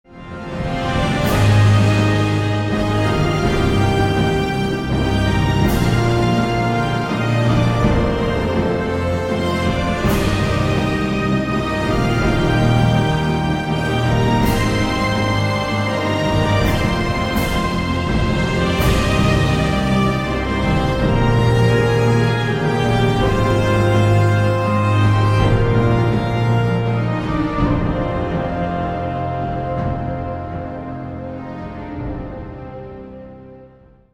инструментальные
OST